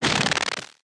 tesla-turret-beam-deflection-5.ogg